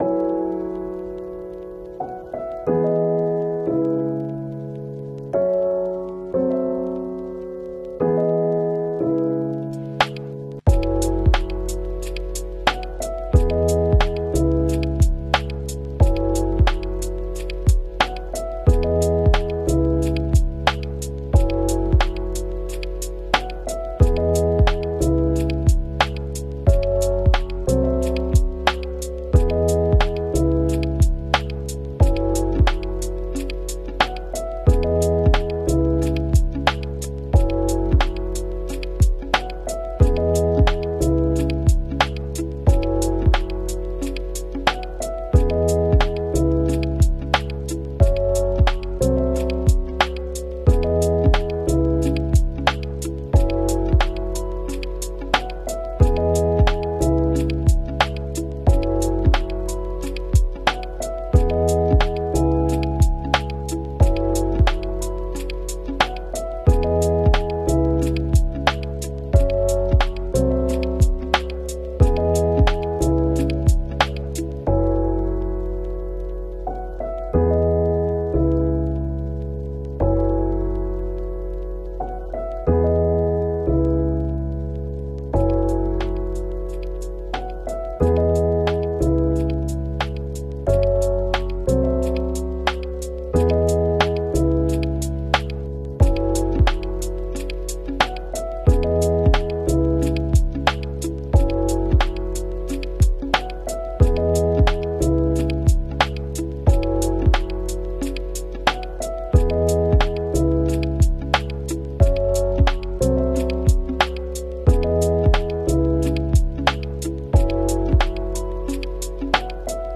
TYPING SOUND TEST NEPTUNE76 #คีย์บอร์ด sound effects free download